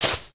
sounds / material / human / step / grass04.ogg
grass04.ogg